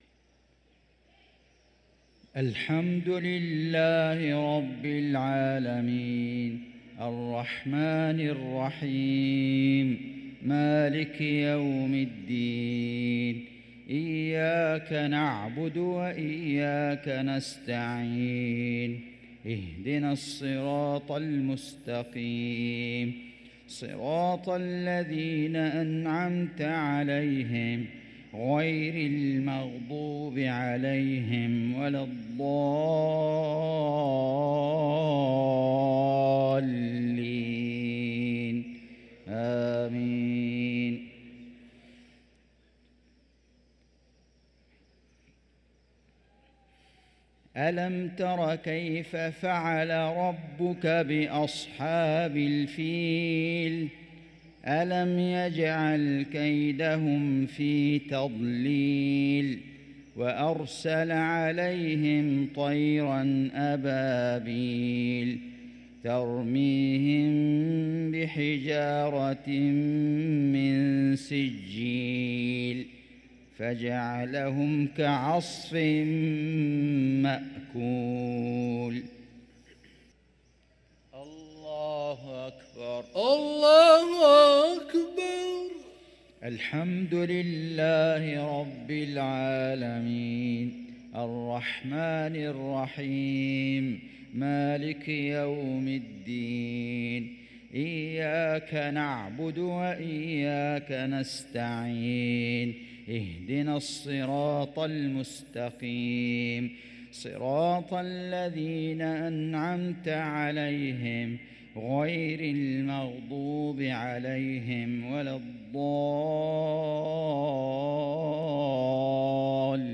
صلاة المغرب للقارئ فيصل غزاوي 18 رجب 1445 هـ
تِلَاوَات الْحَرَمَيْن .